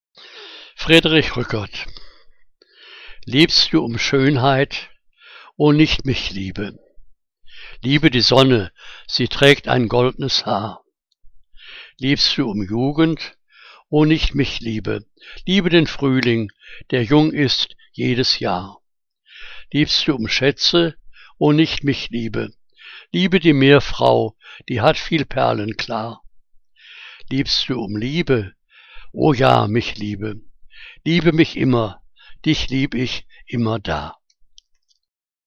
Liebeslyrik deutscher Dichter und Dichterinnen - gesprochen (Friedrich R�ckert)